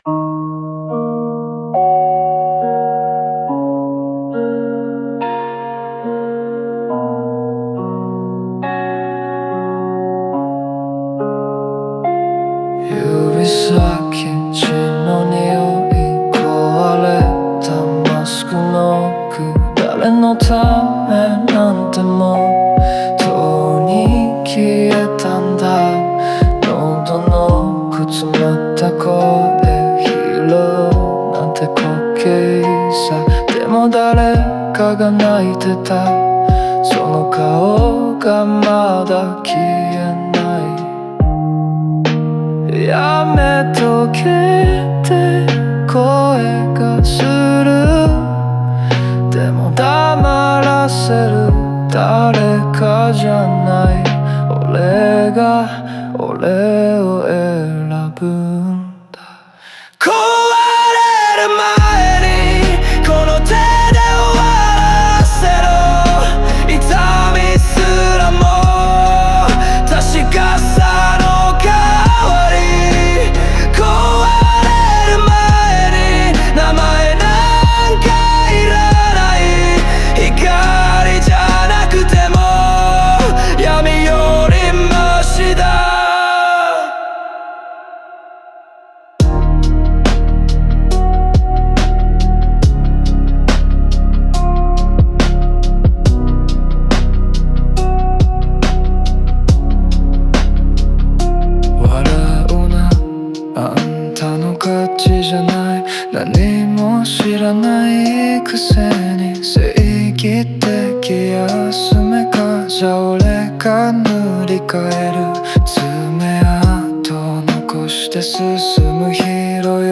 オリジナル曲♪
壊れる寸前の精神状態と、それでも立ち向かう姿を、低音で冷たいビートに乗せて表現しました。